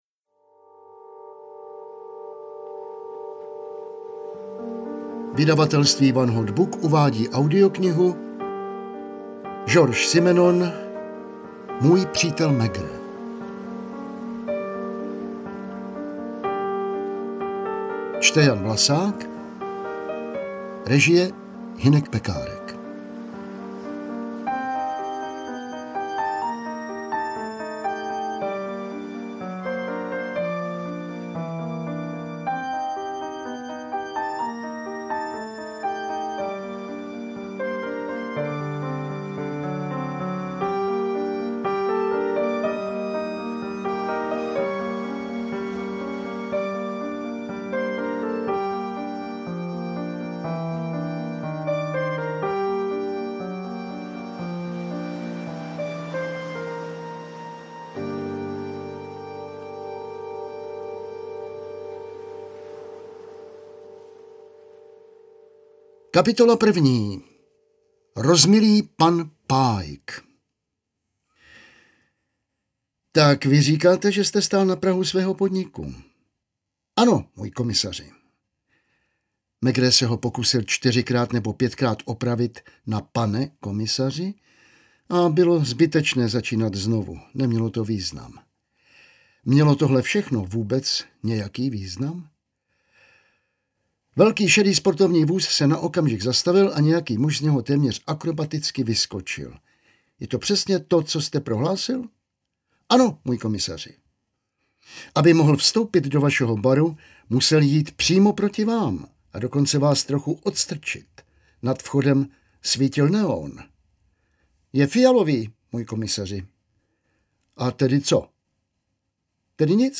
Interpret:  Jan Vlasák